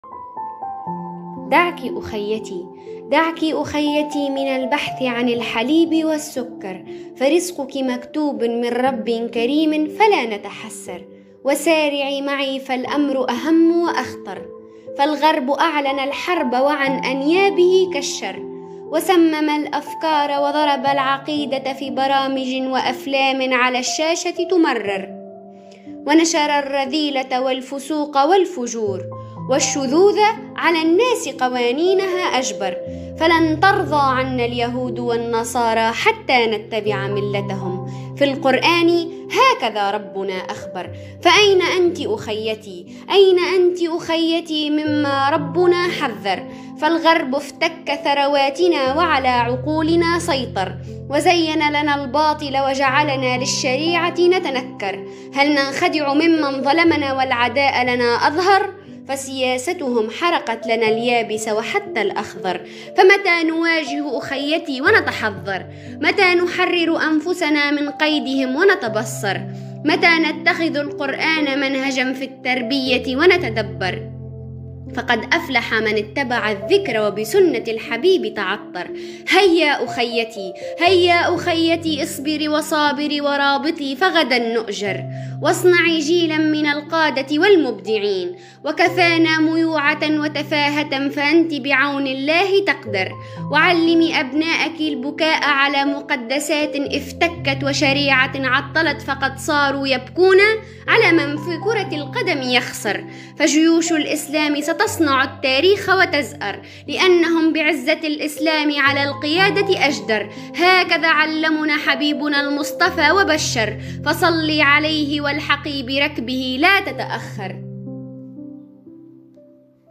قصيدة - دعك أُخيتي القسم النسائي - تونس